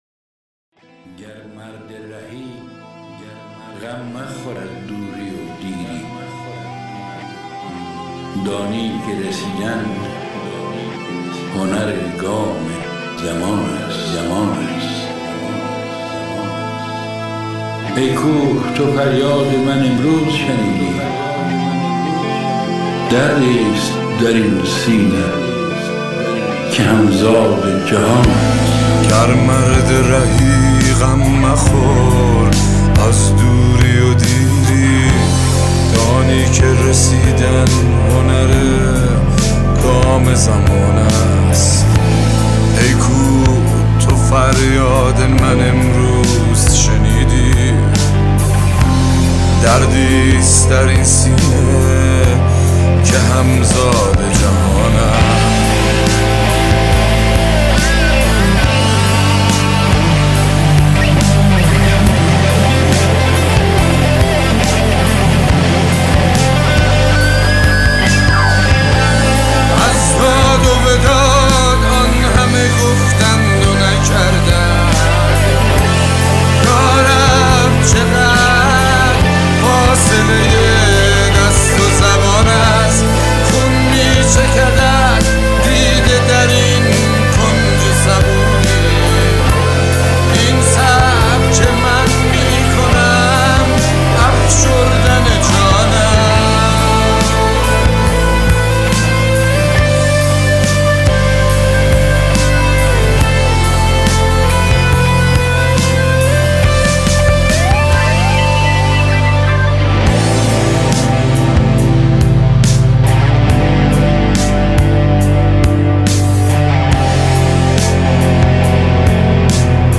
Contemporary